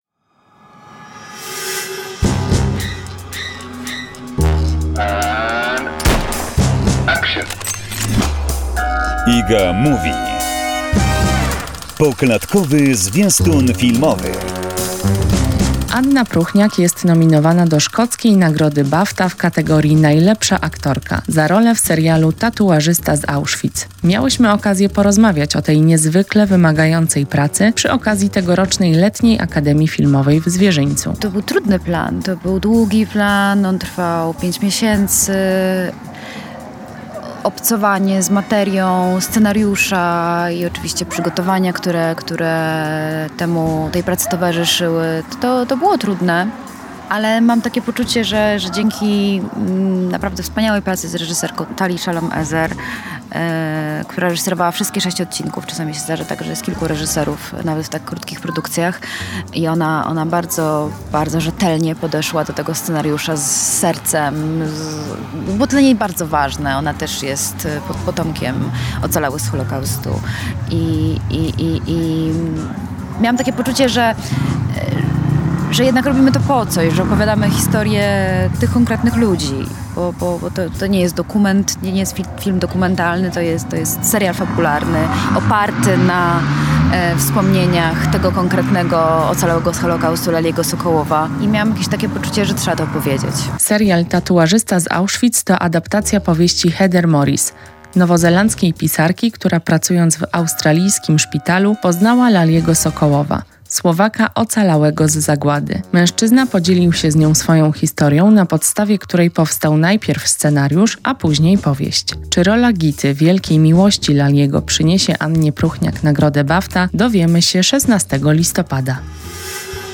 rozmowa z aktorką Anna Próchniak